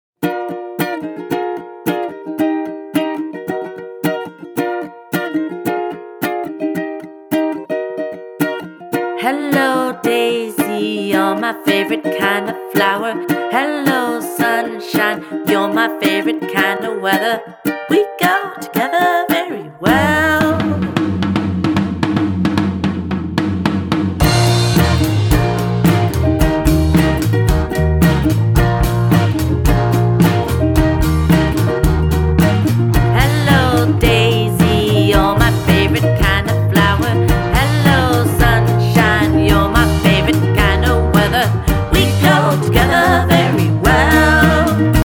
recorded in a small recording studio